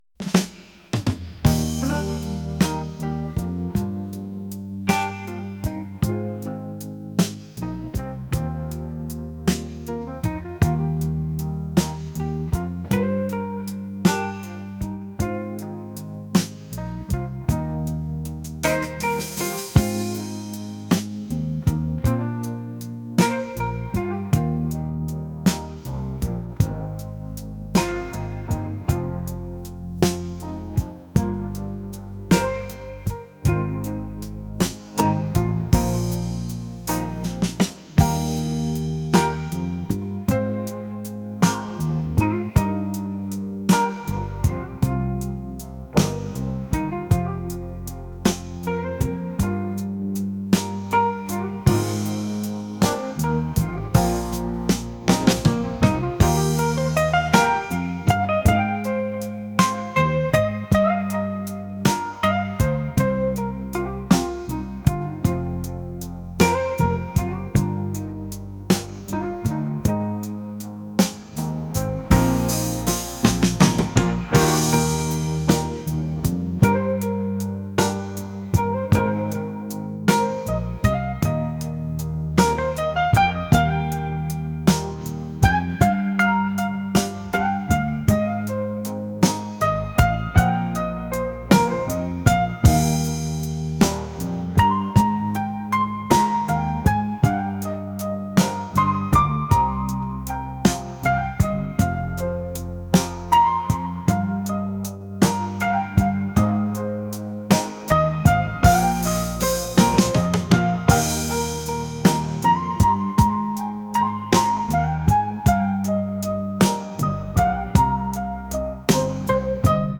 blues | soul & rnb | lounge